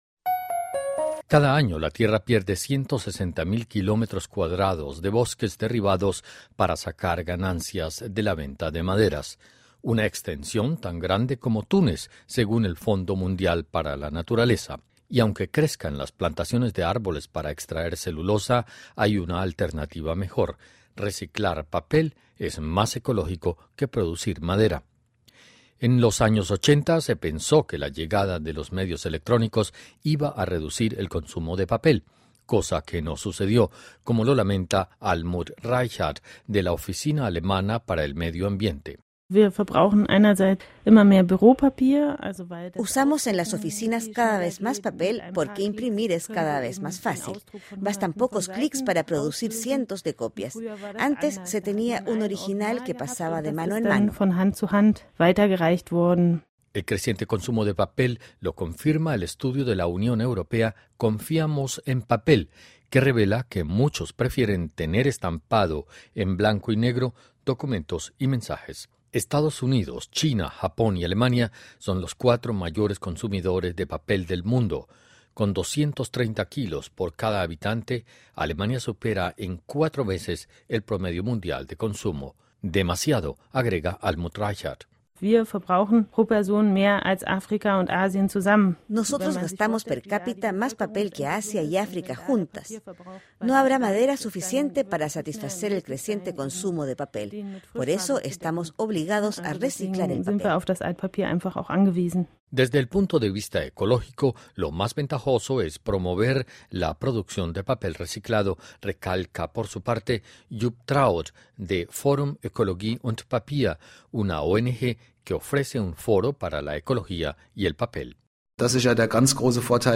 Cada año se pierden 160 mil kilómetros cuadrados de bosques derribados para la venta de madera. Escuche el informe de la Deutsche Welle.